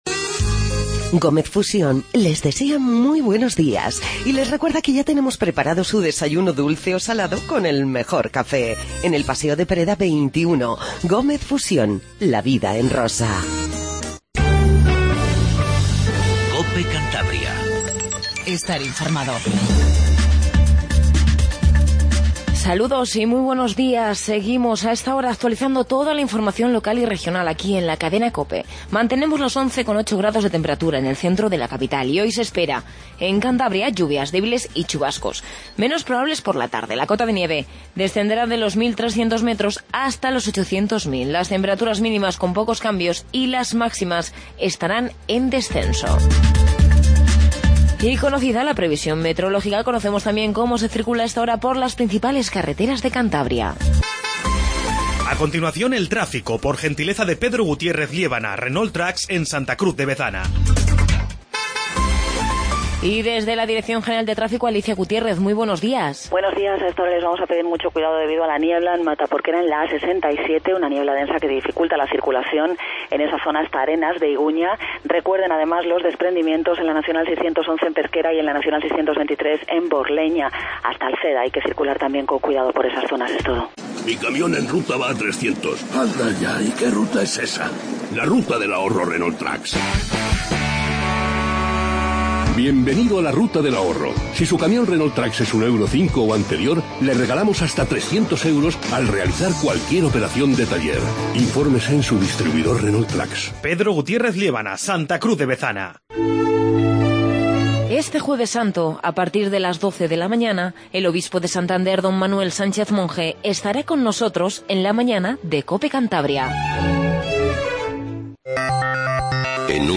AUDIO: Noticias